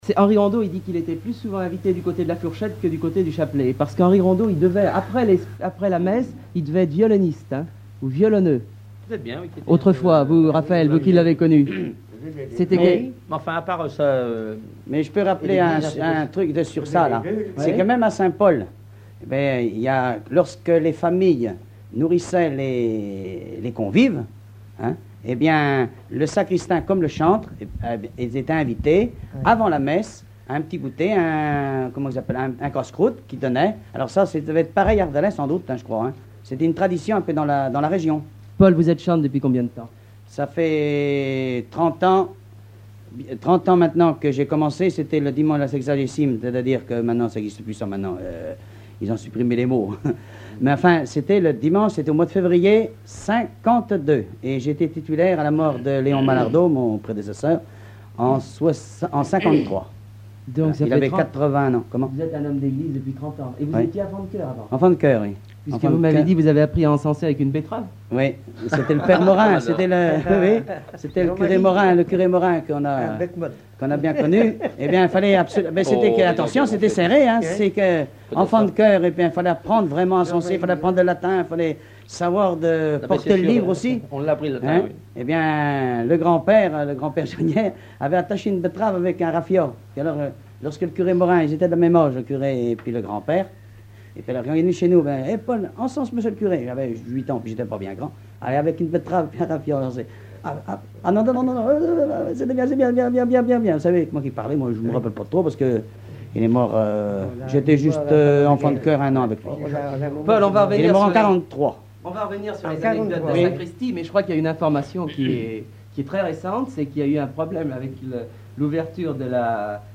Enquête Alouette FM numérisation d'émissions par EthnoDoc
Catégorie Témoignage